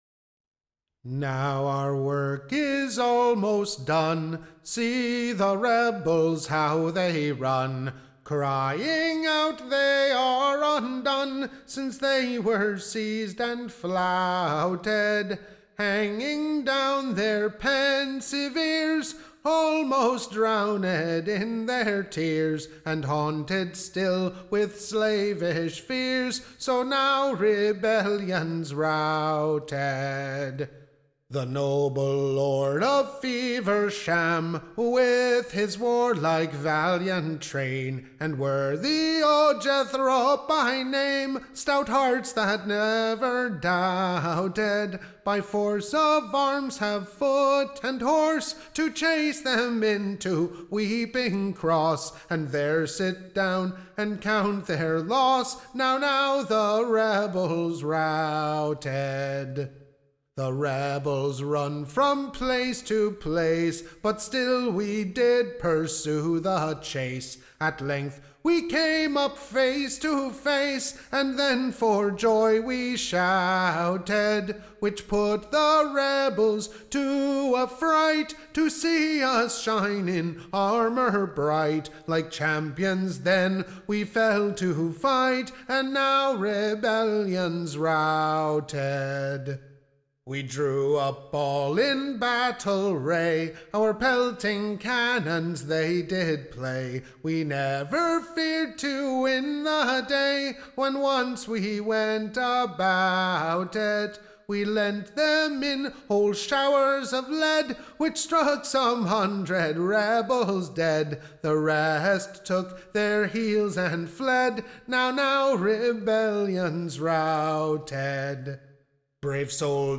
Recording Information Ballad Title The Rebels Totally Routed: / OR THE / Loyal Subjects Satisfaction in the Taking of the late D. of Monmouth, / and the Lord GRAY.